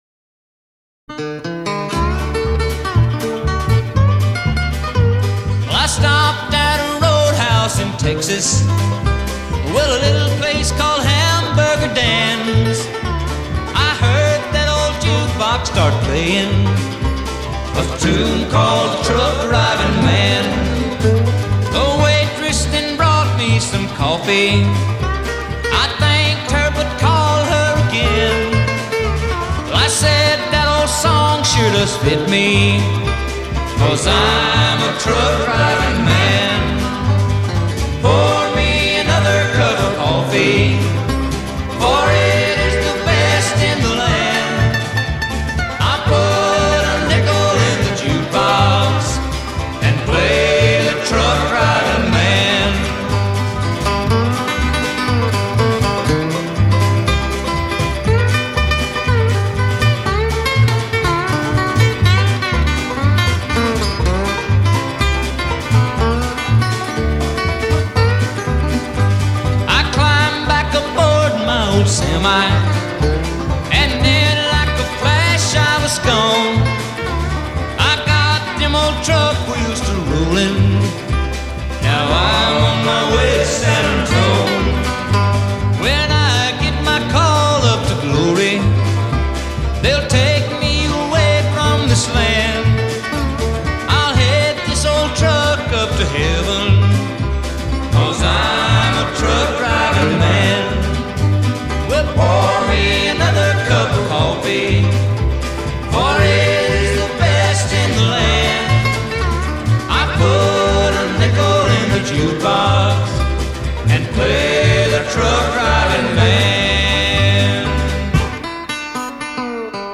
Fantastic Bluegrass.